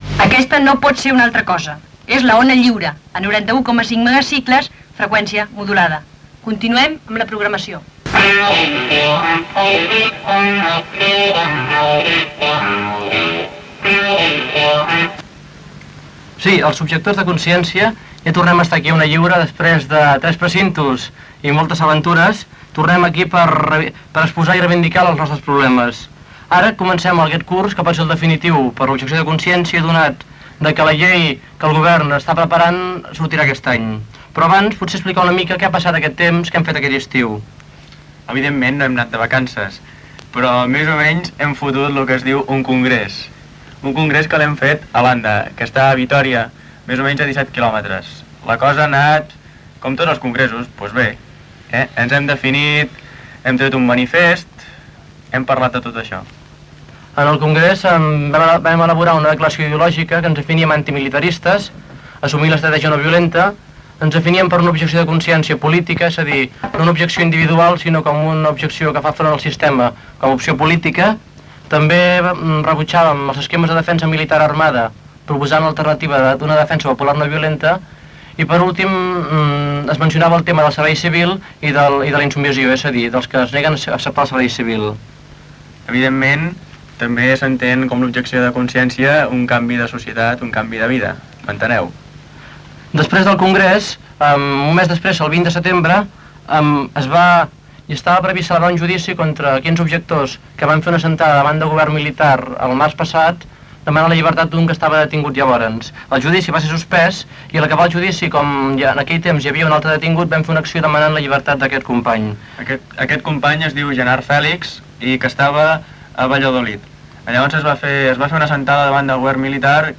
Gènere radiofònic Participació